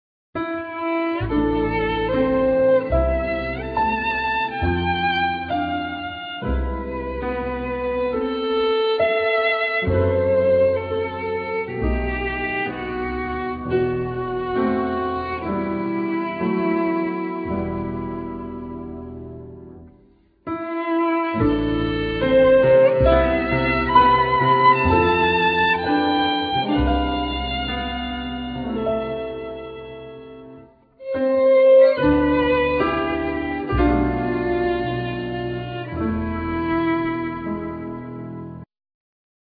Piano
Violin
Bass